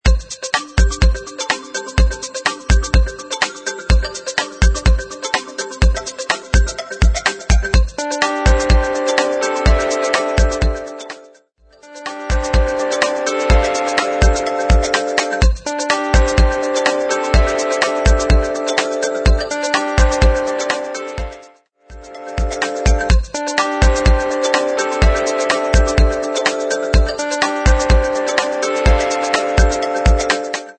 Happy Electronic